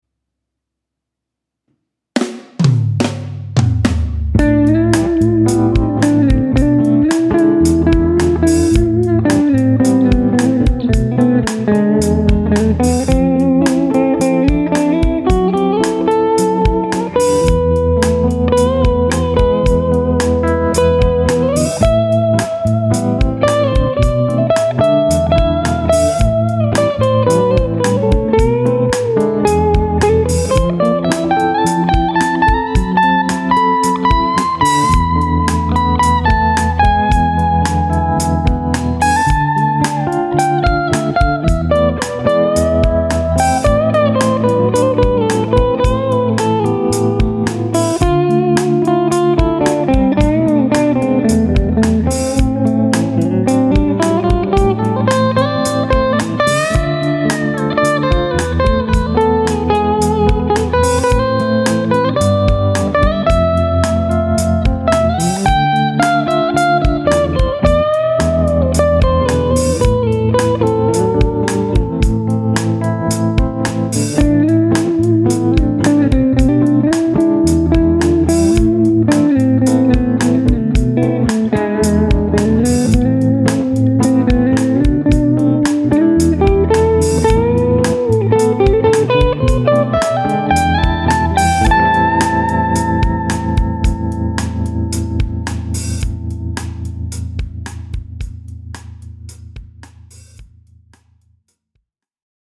Summary: Made out of one of the hardest woods in nature, the Polarity J3 pick produces a warm, but also “spanky” tone that is perfect for leads.
Here’s a little ditty I put together last night to demonstrate how it sounds (I used my Slash L Katie May through a Fender Twin AmpliTube model):
• The pointy tip produces a nice, bright tone, but the wood helps balance that out with some warmth in the mid-range.